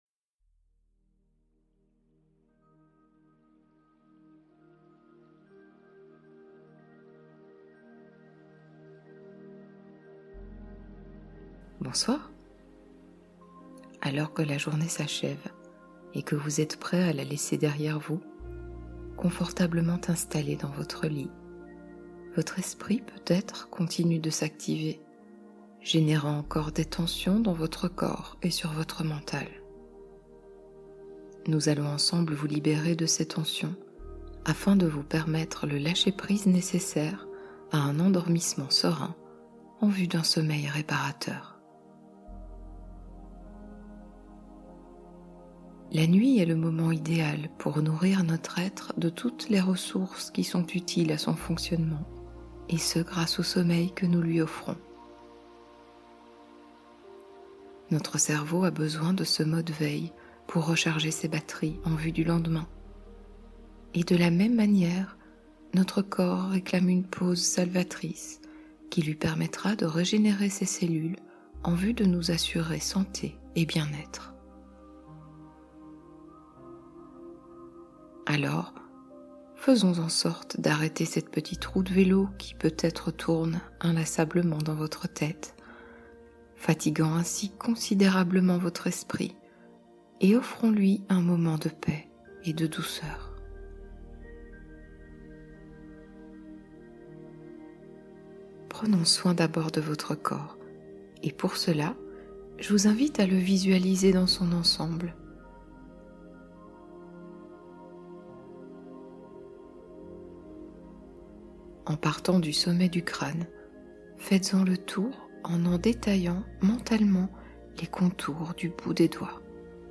Voyage vers le sommeil : hypnose douce d’endormissement